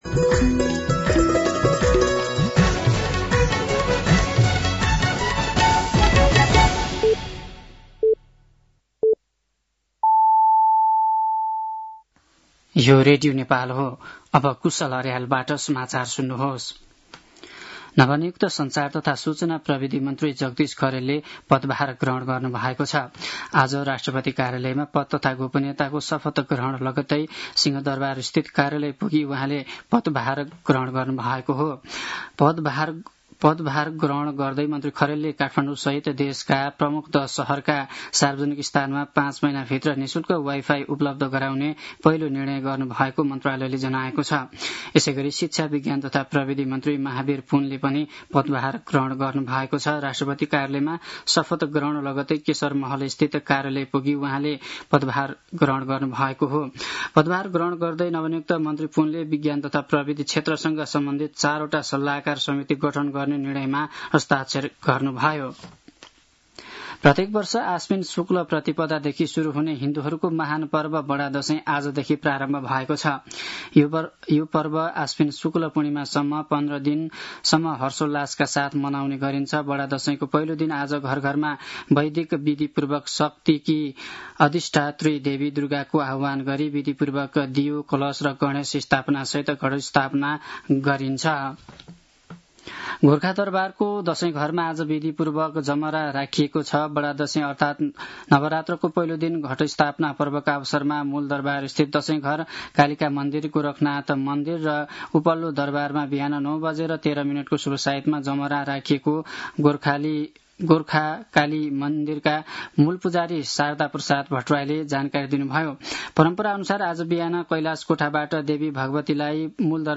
साँझ ५ बजेको नेपाली समाचार : ६ असोज , २०८२